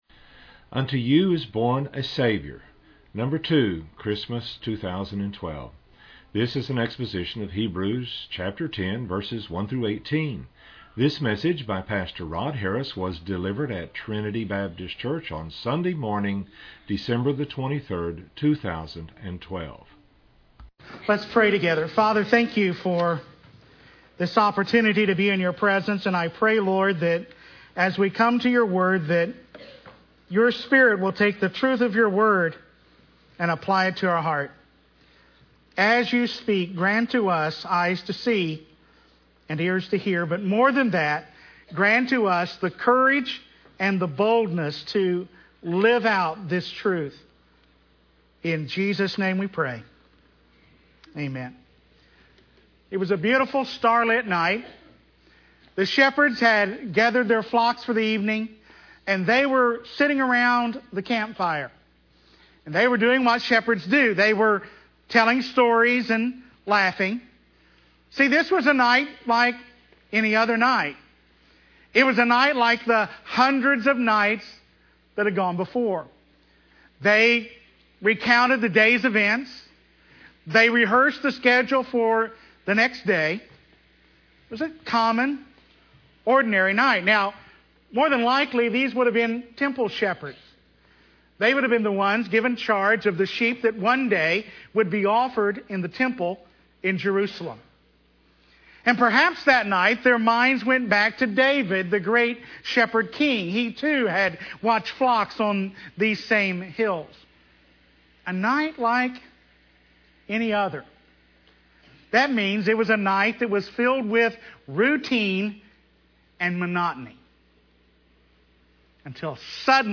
These two messages on the coming of our Lord into this world were delivered during the 2012 Advent season.